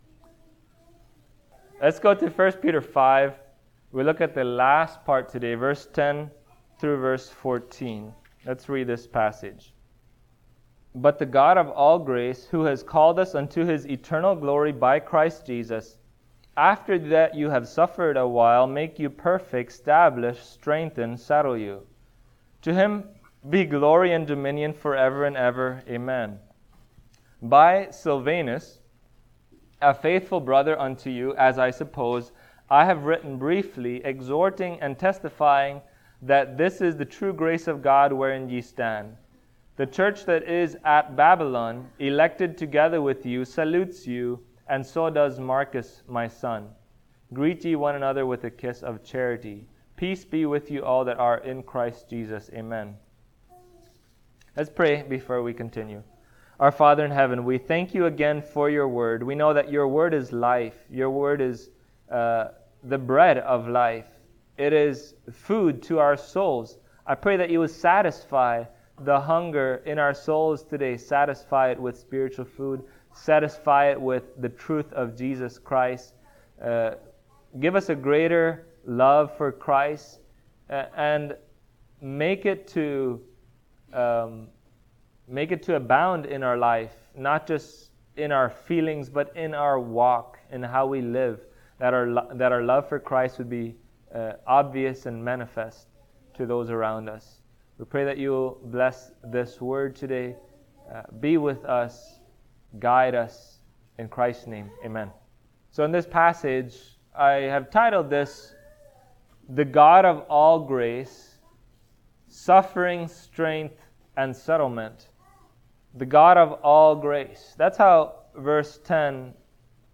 1 Peter Passage: 1 Peter 5:10-14 Service Type: Sunday Morning Topics